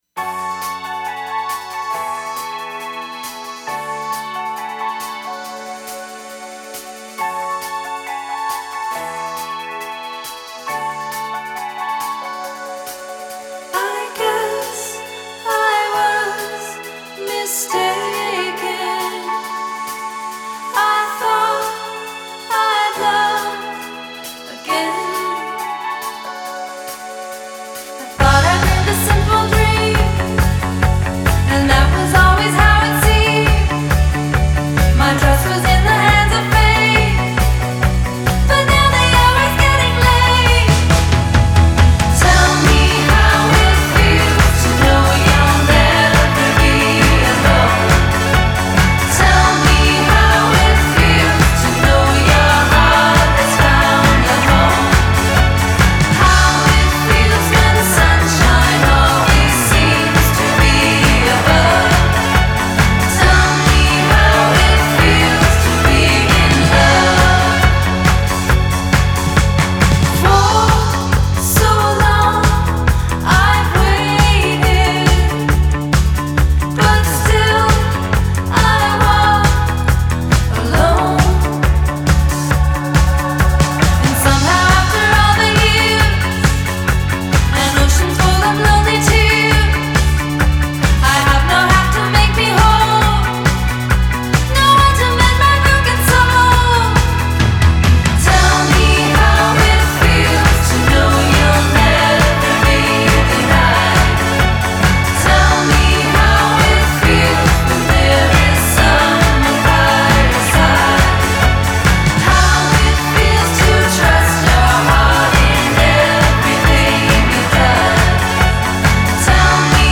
Genre: Indie Pop, Synthpop, Female Vocal